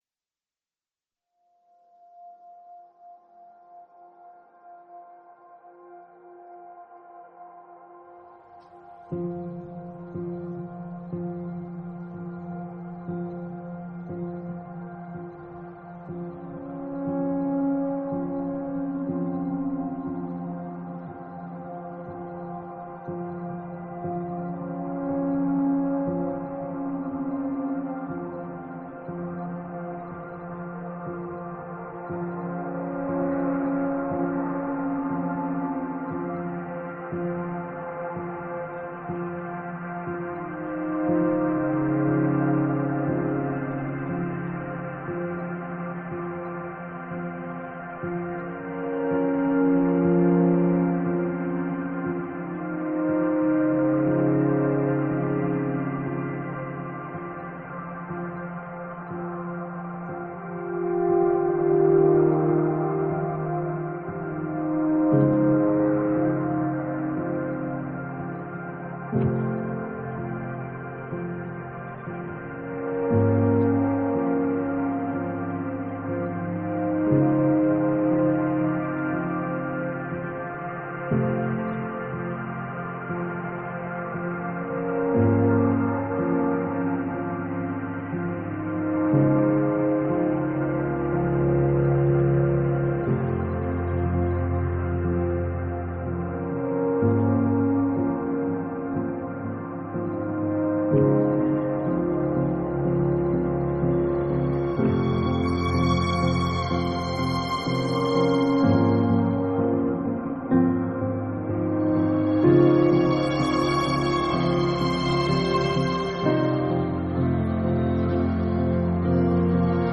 Neo-Classical, Ambient, Modern Classical